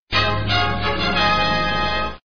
GameWin.mp3